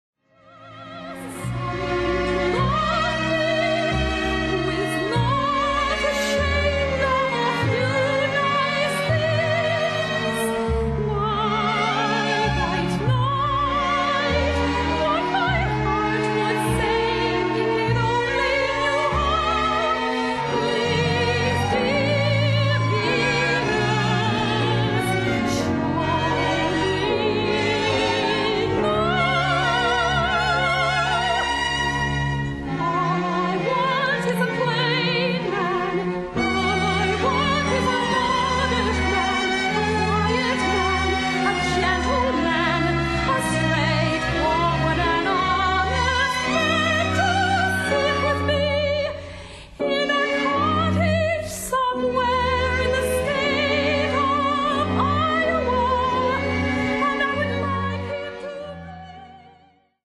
Category: Broadway, Film and Shows